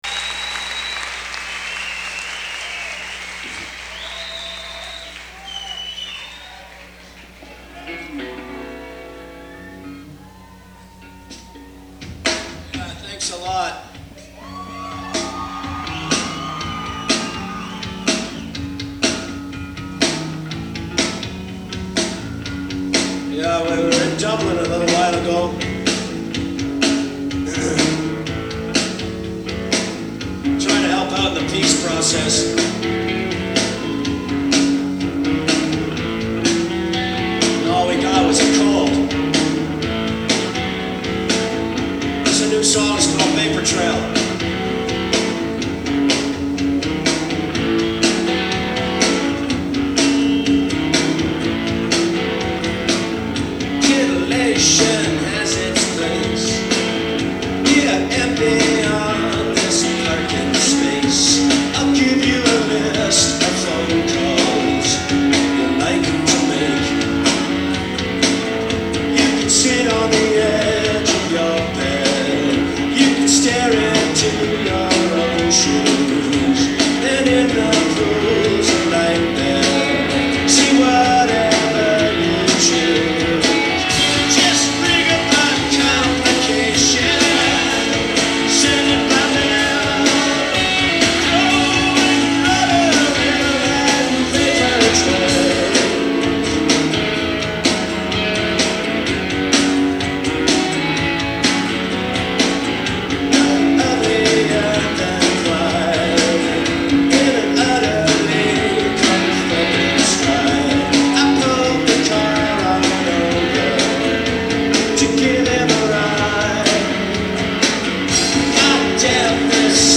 Live In 1997